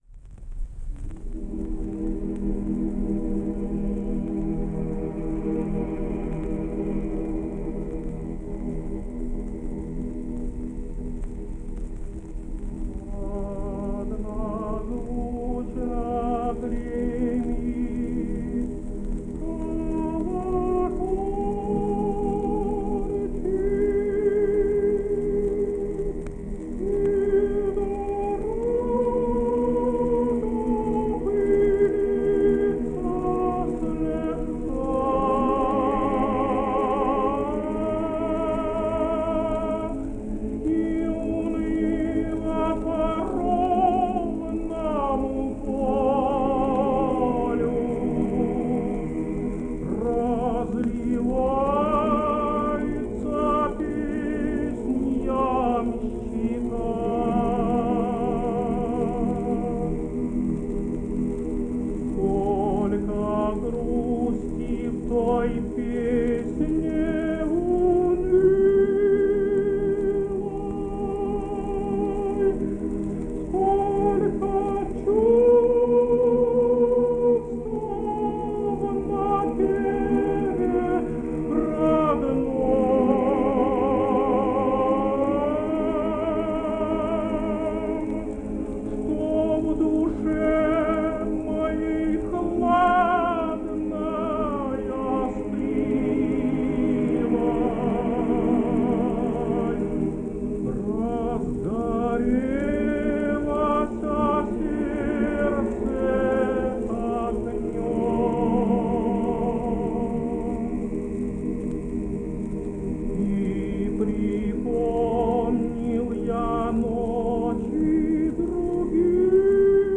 set in a lower key, the harmonies built around the warm, nut-brown glow of the basses and baritones, admirably support expressive, fuller, honey golden toned voice